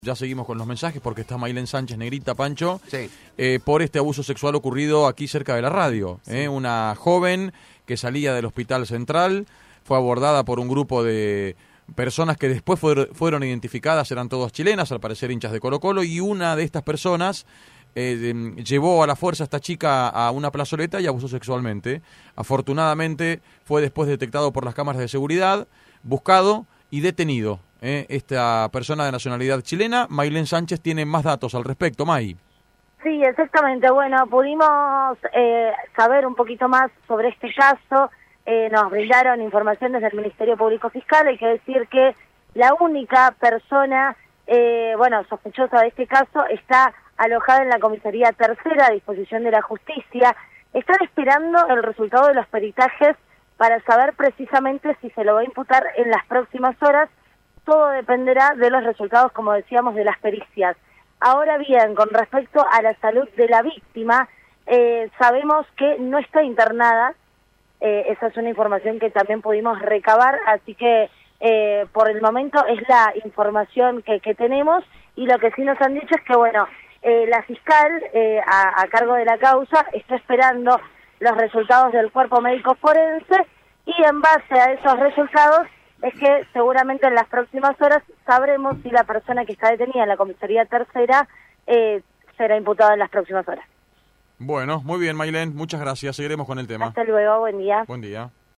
Móvil de LVDiez- Caso abuso sexual de mujer por hincha de Colo Colo en Ciudad